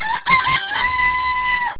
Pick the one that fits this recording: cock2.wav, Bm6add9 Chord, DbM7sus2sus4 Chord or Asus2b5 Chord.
cock2.wav